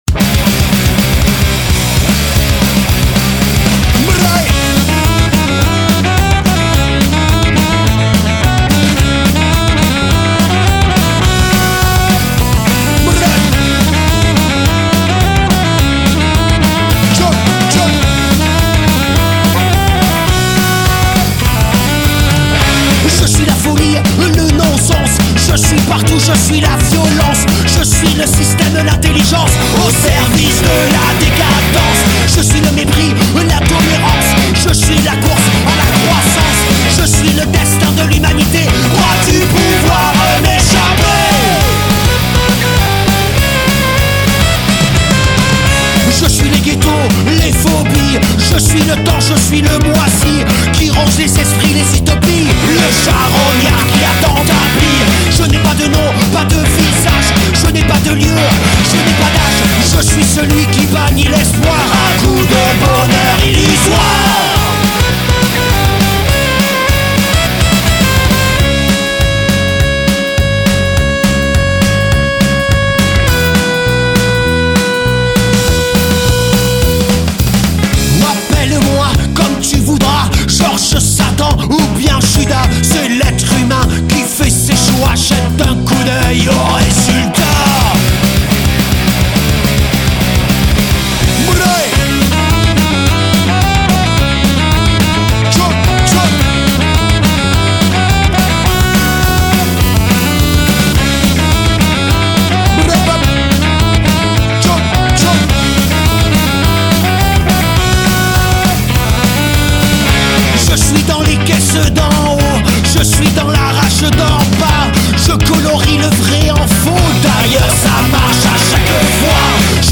La musique carrée comme il faut, sans faux cols
et une voix qui passe impeccable.